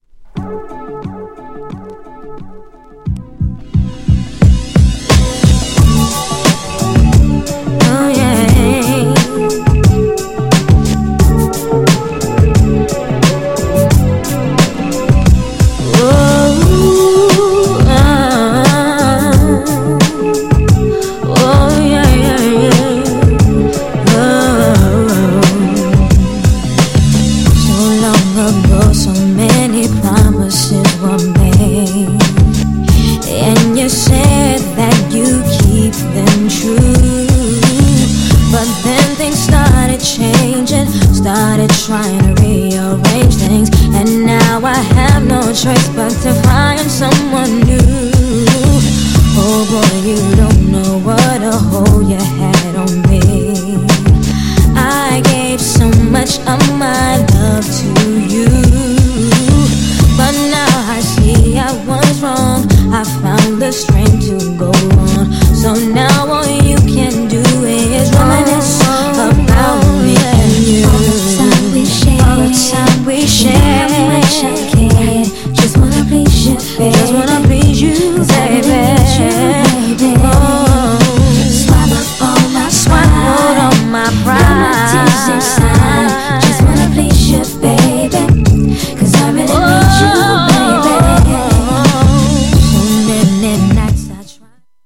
GENRE R&B
BPM 81〜85BPM
# 90s_HIP_HOP_SOUL
# SMOOTH_R&B
# 女性VOCAL_R&B